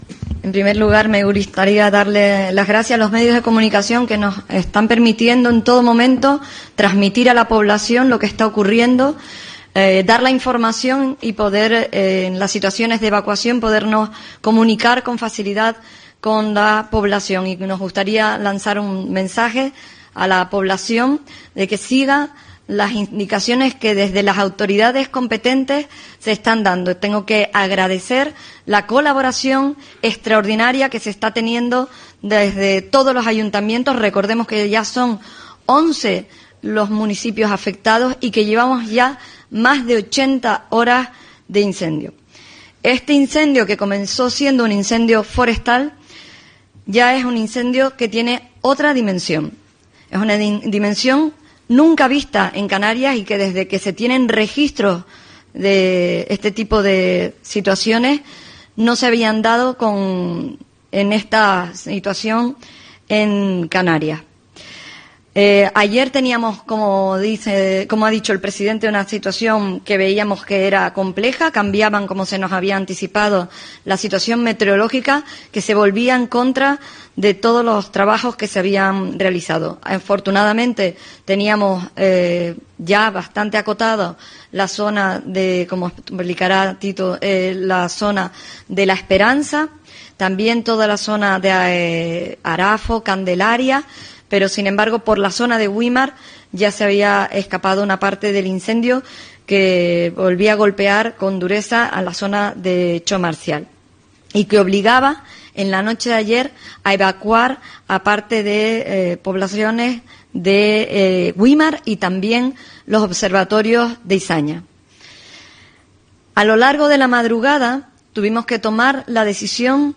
Así se ha puesto este sábado de manifiesto durante la rueda de prensa de las 11.30 horas para actualizar la información sobre la evolución del incendio.